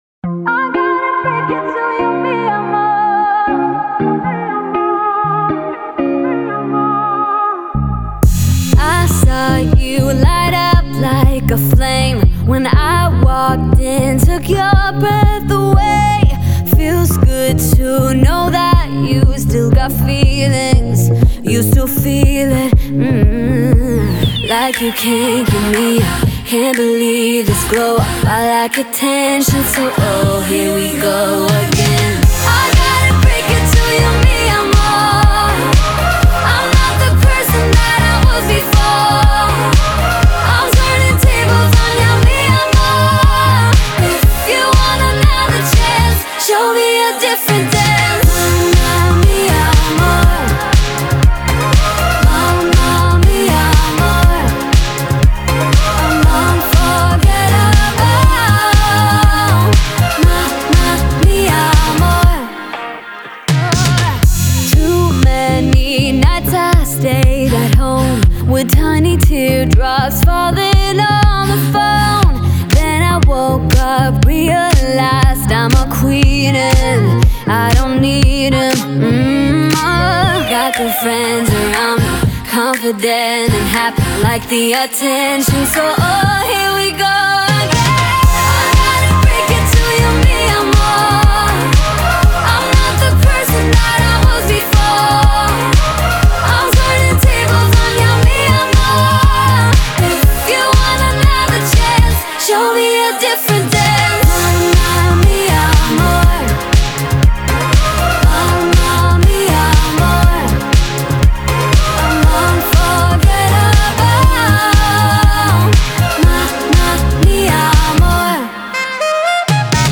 это романтическая песня в жанре латин-поп